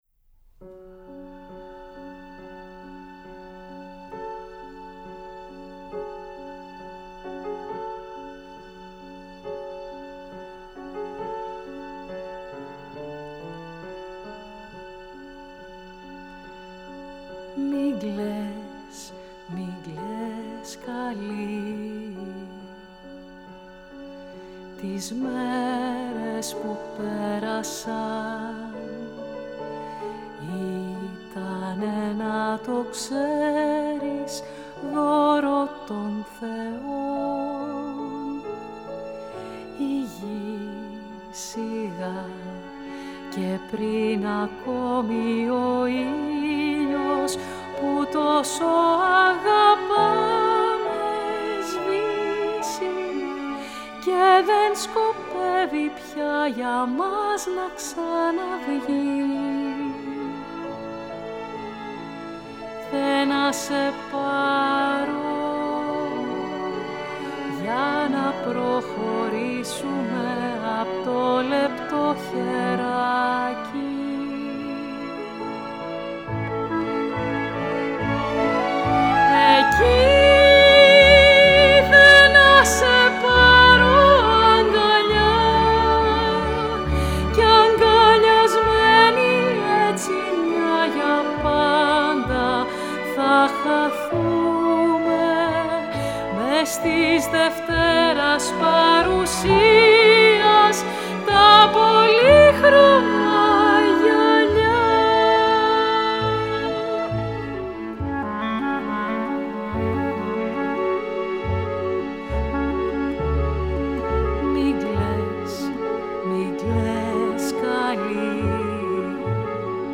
Ηχογράφηση στο STUDIO B, Παρασκευή 16 Οκτωβρίου 2020
Στο πιάνο ο συνθέτης